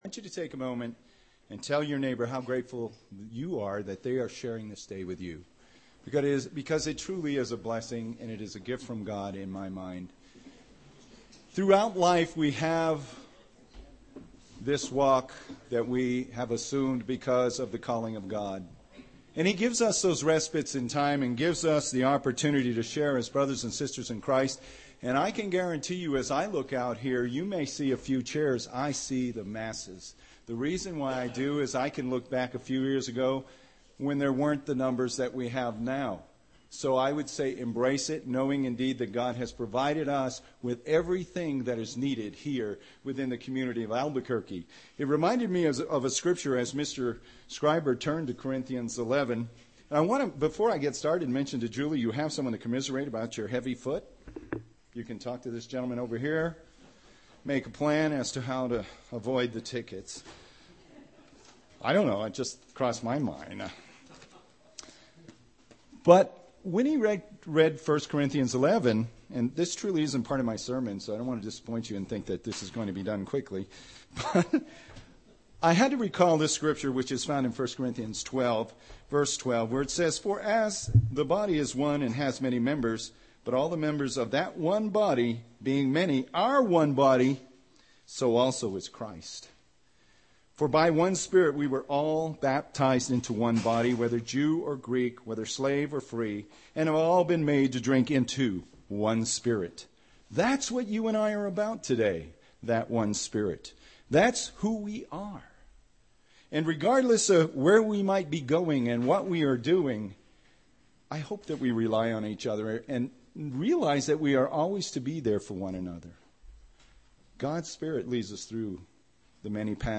UCG Sermon Studying the bible?
Given in Albuquerque, NM